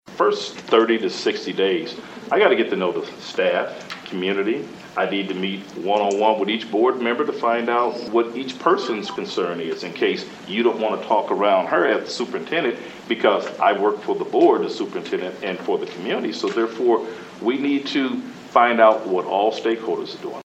Monday night the Burr Oak School Board held second round interviews for the position.